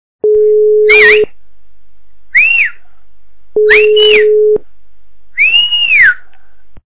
» Звуки » Люди фразы » Зазывающий - свист
При прослушивании Зазывающий - свист качество понижено и присутствуют гудки.
Звук Зазывающий - свист